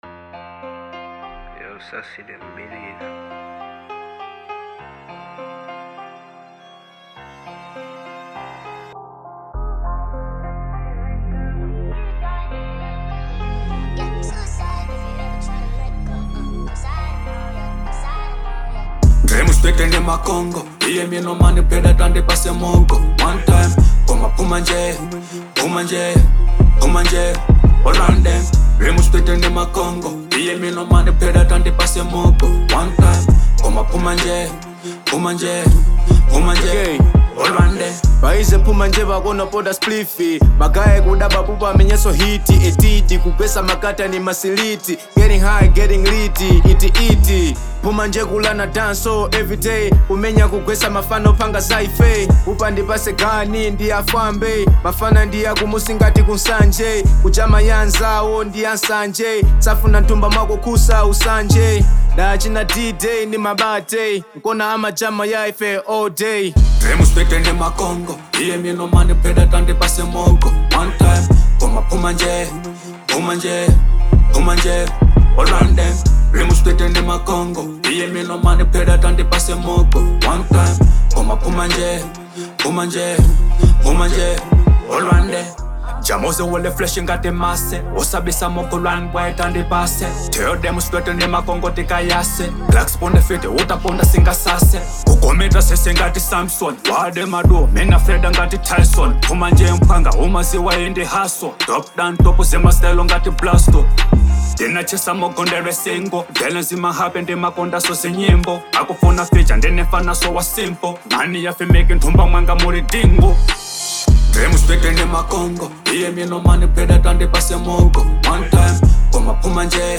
Genre : Hip hop/Dancehall